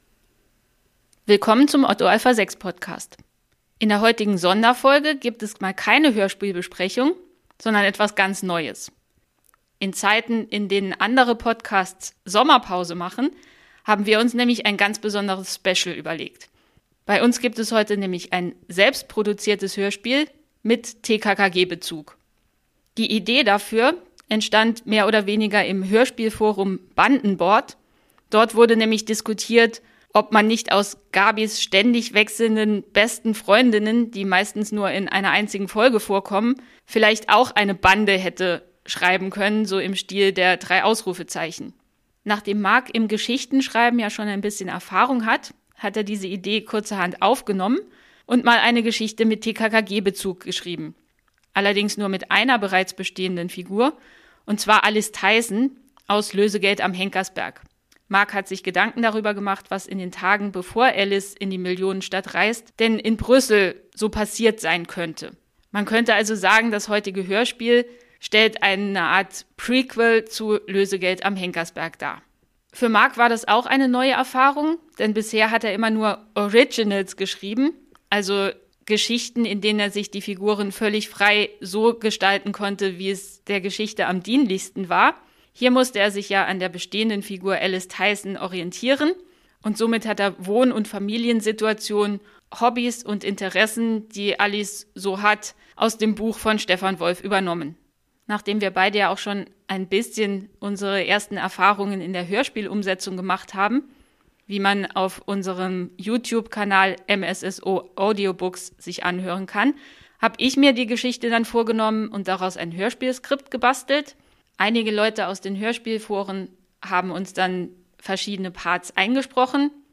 Als kleines Sommerspecial gibt es heute ein selbsterfundenes Hörspiel über Alice Theisen.